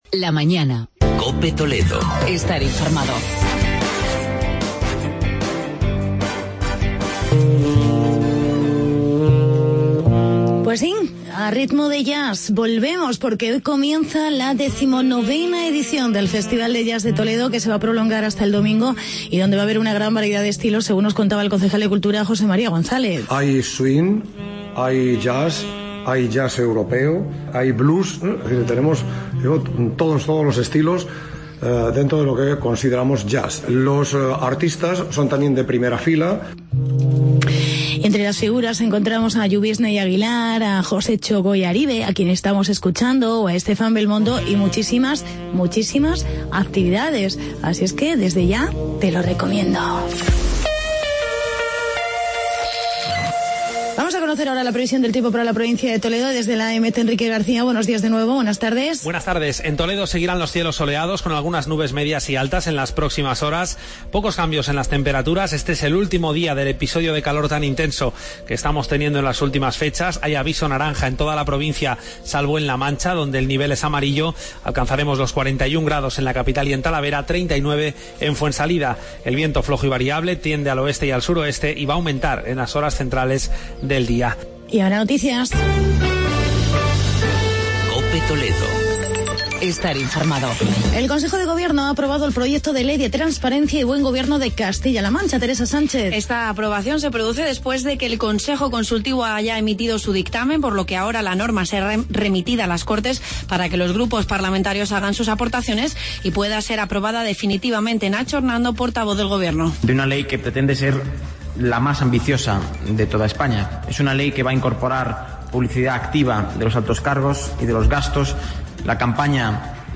Comienza el Festival de Jazz Ciudad de Toledo y entrevista con el concejal de festejos, José Luis Muelas sobre la Festividad de la Virgen del Prado en Talavera.